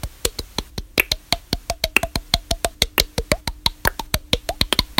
风暴雨隆隆声
描述：风暴雨隆隆声
Tag: 隆隆 风暴